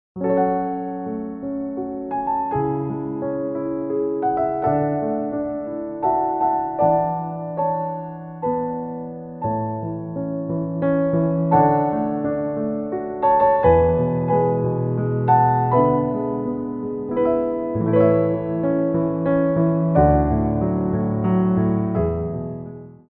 Port de Bras 2
3/4 (8x8)